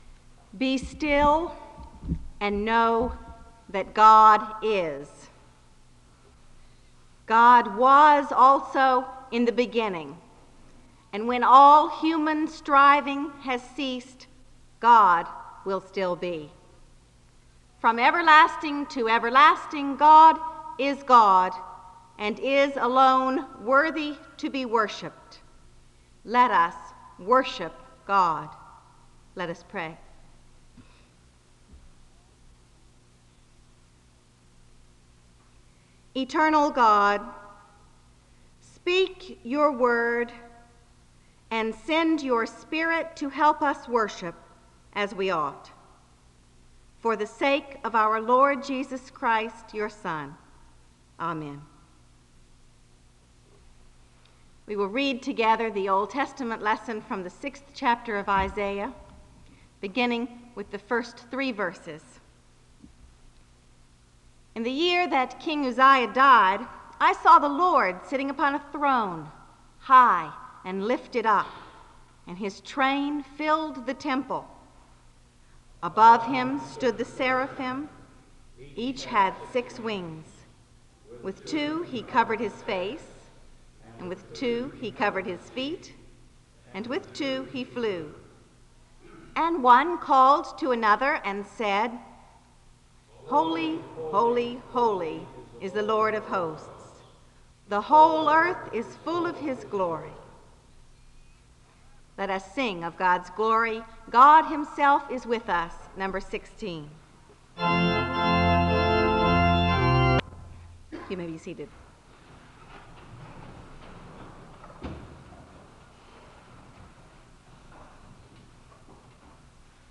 The service opens with a word of prayer and a reading from Isaiah 6:1-3 (00:00-01:50).
The choir leads in a song of worship (07:18-09:29). The public reading of Scripture comes from Luke 5:1-11 (09:30-11:26).
Resource type Audio Citation Archives and Special Collections, Library at Southeastern, Southeastern Baptist Theological Seminary, Wake Forest, NC.